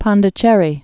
(pŏndĭ-chĕrē, -shĕrē)